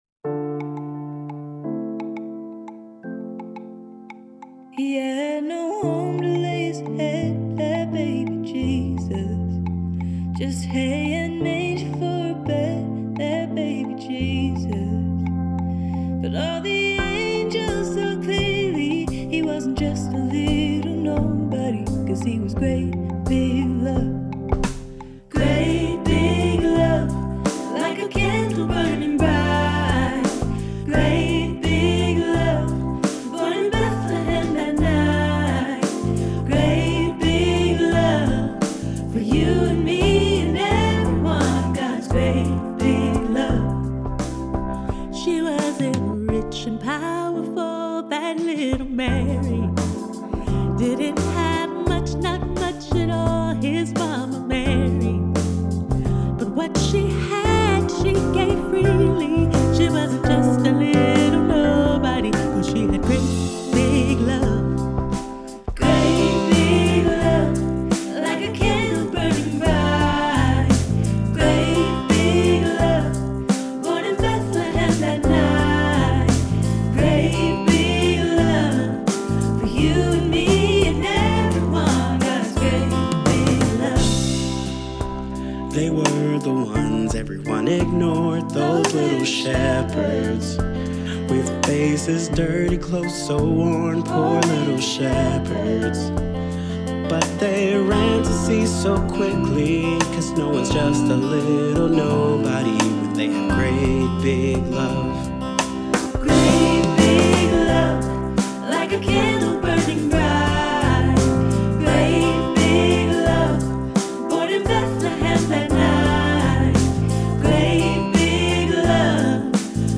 Children’s Songs for Advent and Christmas